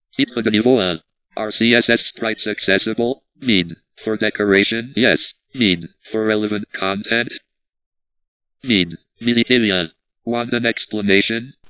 audio file so that you hear the rating with my screen reader. (by the way, “veed” is French for “empty”).
screenreader.ogg